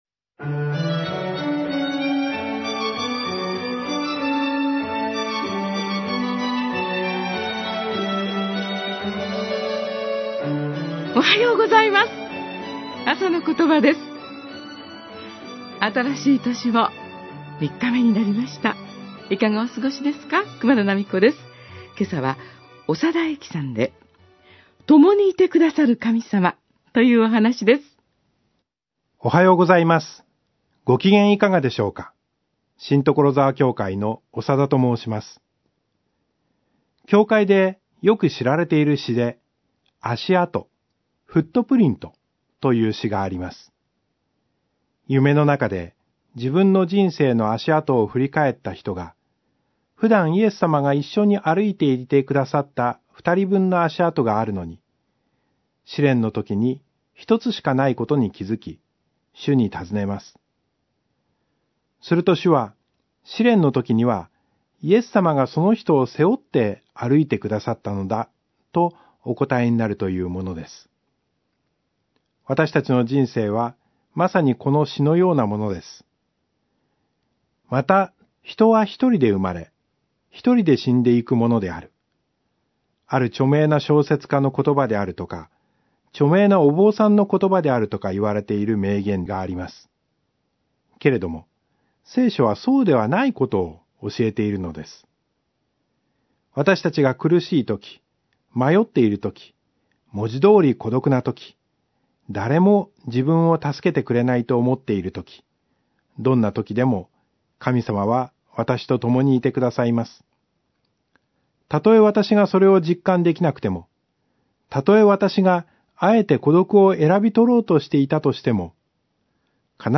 あさのことば 2019年1月3日（木）放送